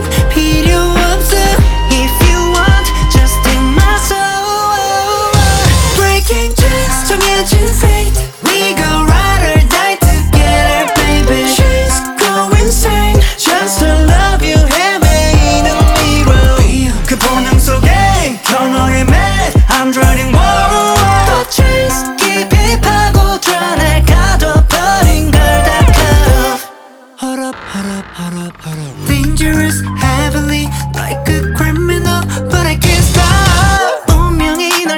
K-Pop Pop
Жанр: Поп музыка